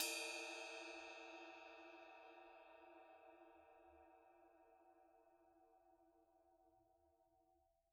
Percussion
susCymb1-hitstick_mp_rr1.wav